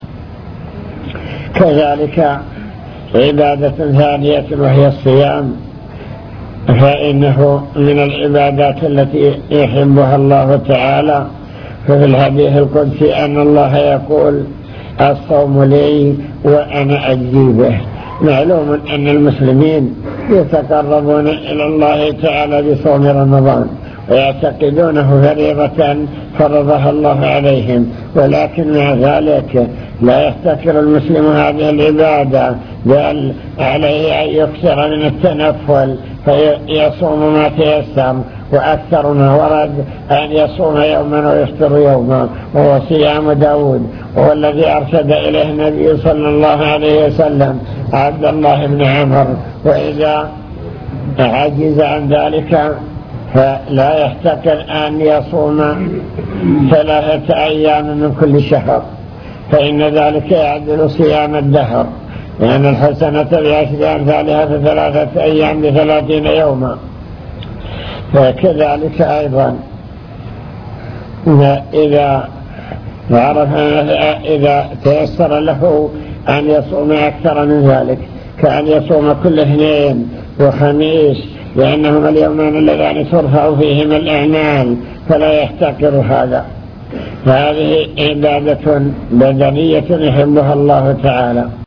المكتبة الصوتية  تسجيلات - محاضرات ودروس  نوافل العبادات وأنواعها العبادات الفعلية